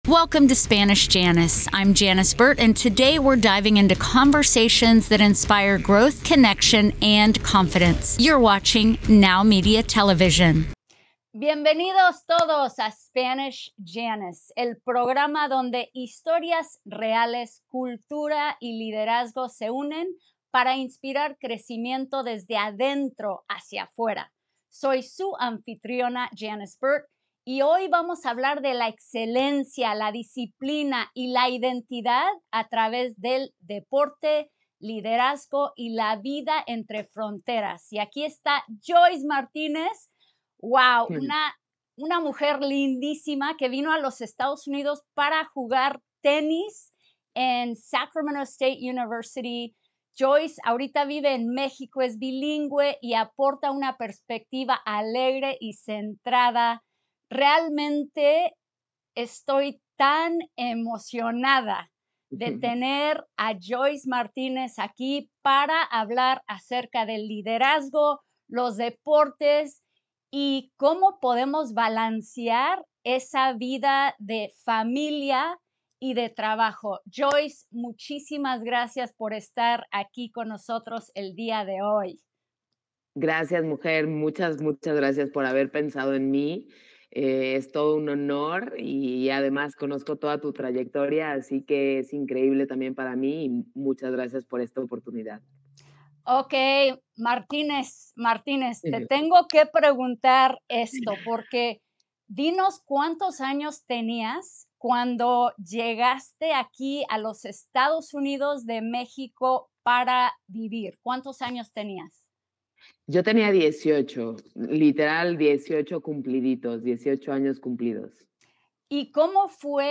This real conversation dives into overcoming self-doubt, handling pressure, the importance of encouragement, and how personal growth is built through consistency and mindset.